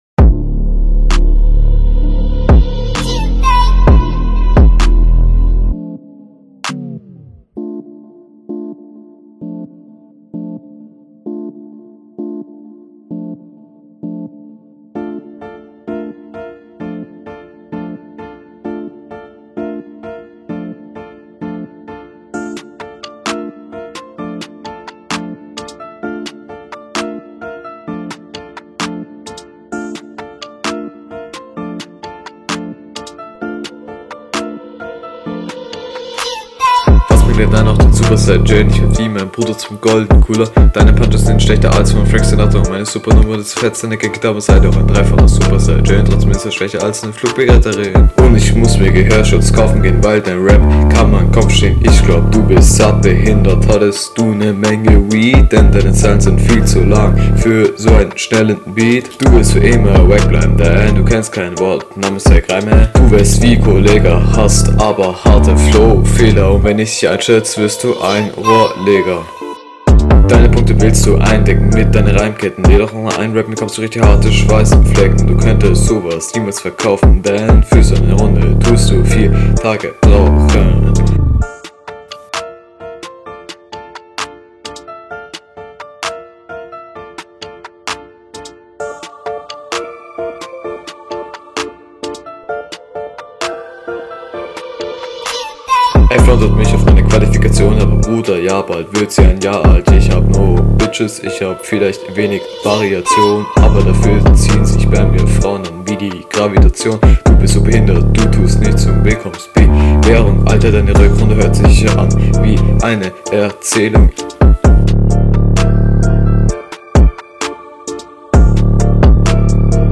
Abmischen ist wohl nicht so deine Stärke.